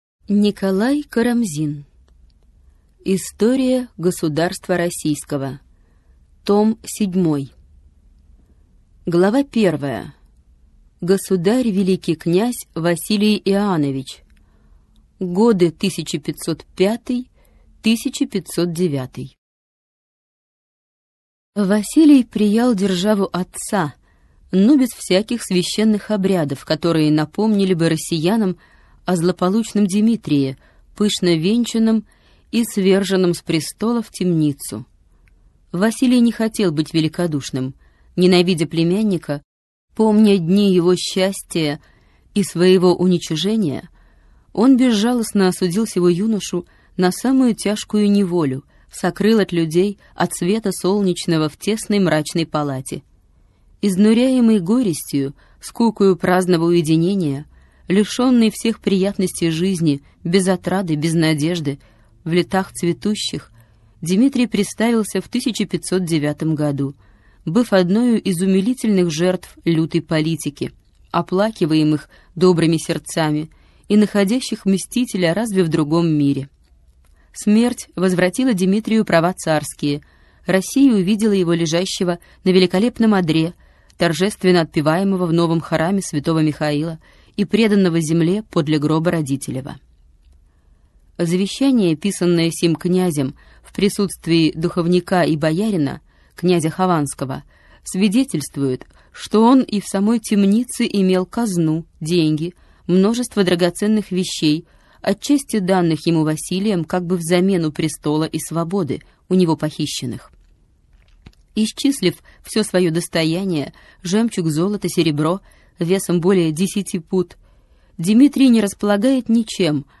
Аудиокнига История государства Российского. Том 7. Государь Великий князь Василий Иоаннович. 1505-1533 года | Библиотека аудиокниг